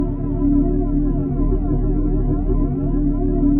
sci-fi_forcefield_hum_loop_05.wav